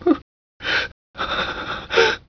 DATrem2_Whimper.wav